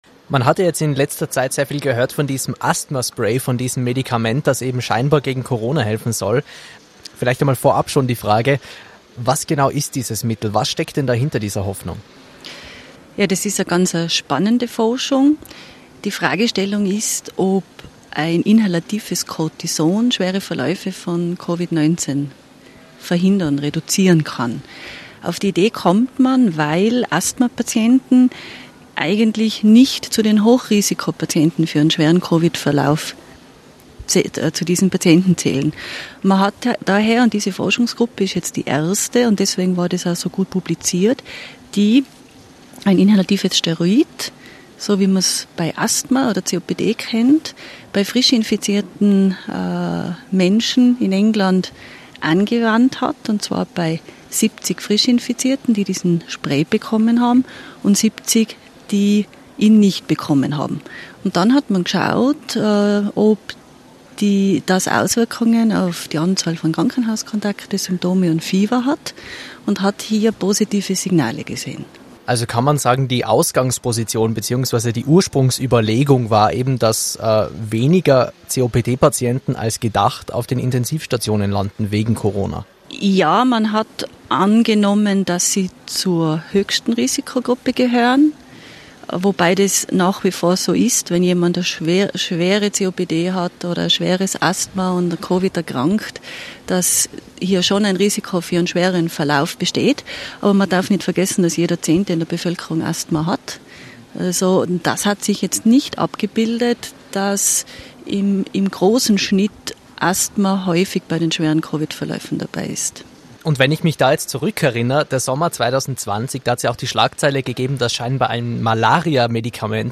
Das sagt die Expertin Wer an diesem Thema Interesse hat